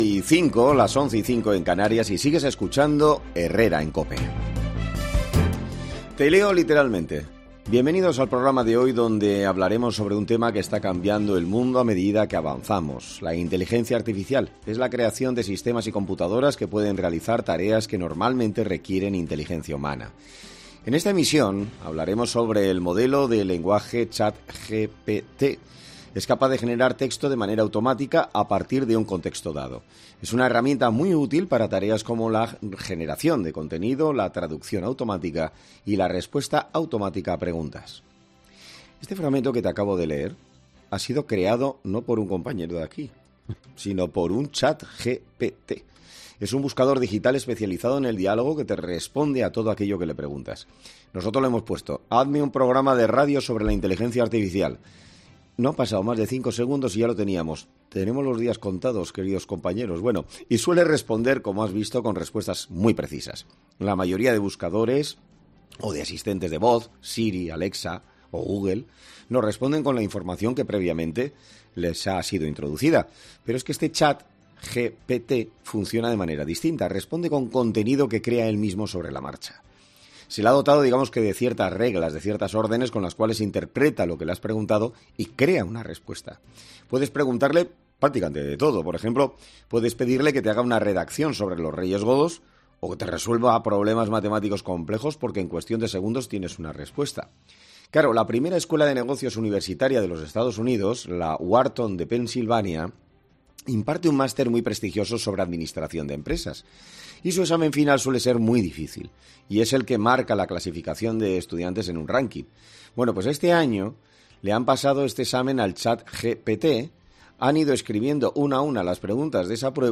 Un profesor de universidad habla en 'Herrera en COPE' de la herramienta que han tenido que prohibir a la clase